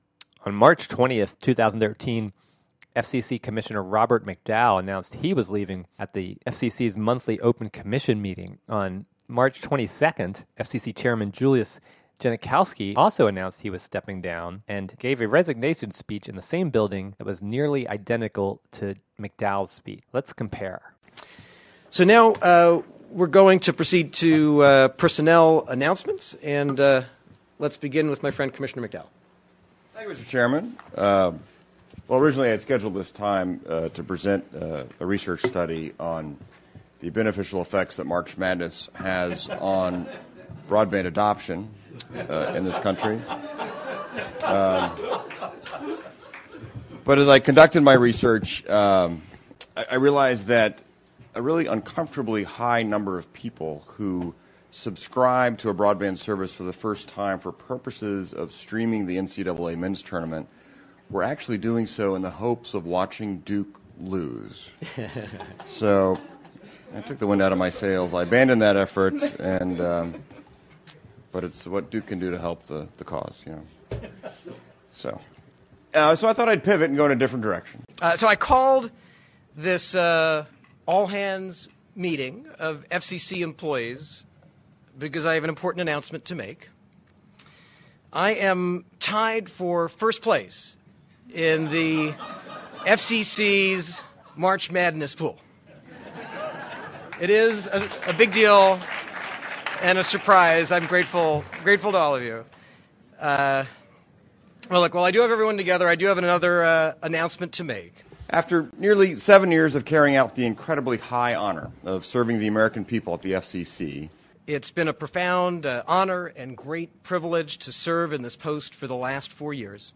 News report about FCC Chairman Jules Genaschowski announcing his resignation. (Audio)
Both their resignation announcement speeches, two days apart, in the same building, were nearly identical, and this report compares the audio.